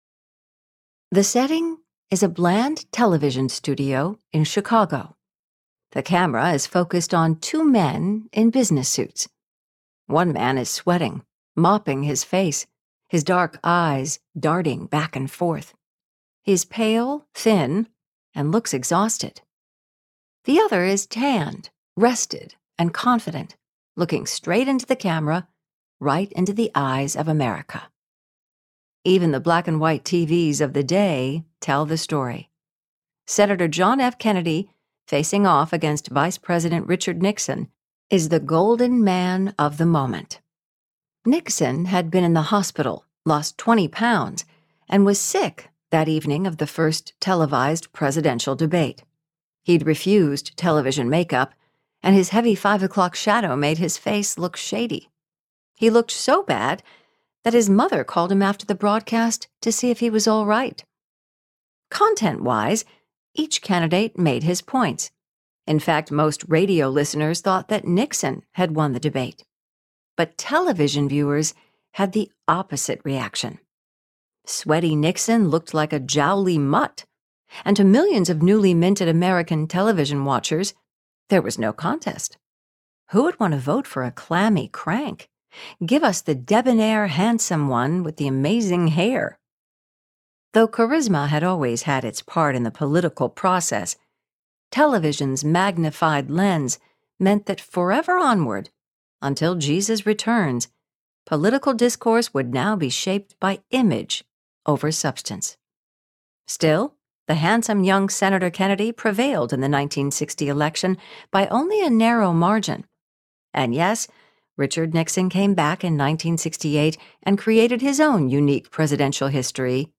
Jesus Revolution Audiobook
Narrator